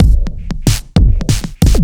OTG_Kit10_Wonk_130a.wav